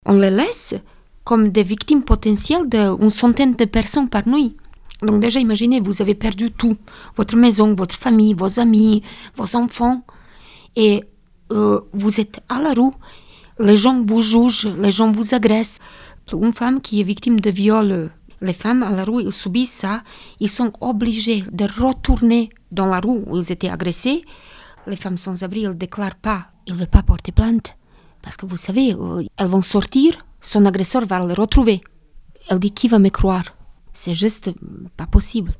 était à notre antenne. Selon elle, chaque femme sans abri est une victime potentielle.